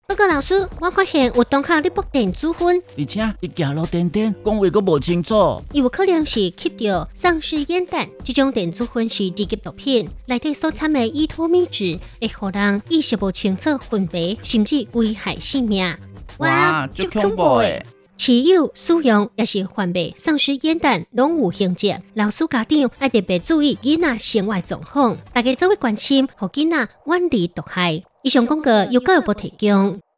轉知 教育部製作防制依托咪酯廣播廣告